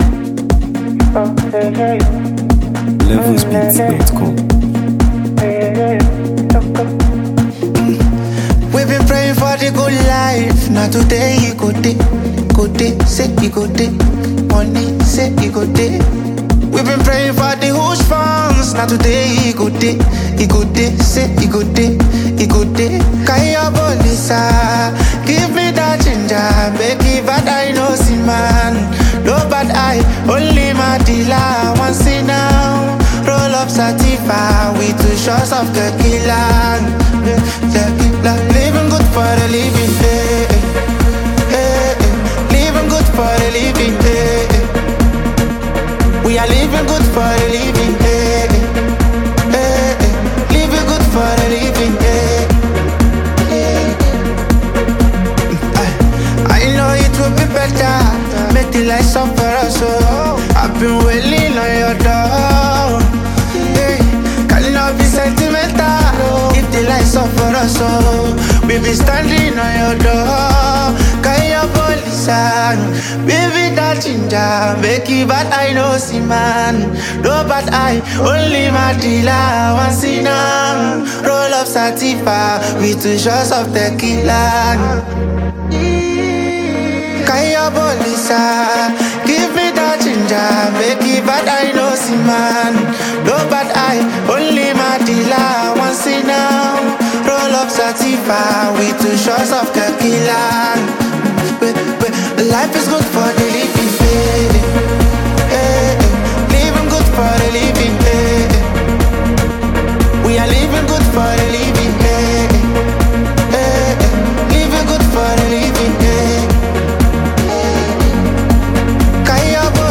heartfelt vocals and meaningful lyrics
With its smooth rhythm, emotional depth